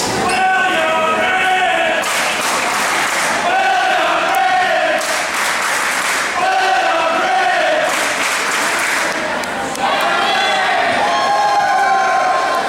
chant-sound.mp3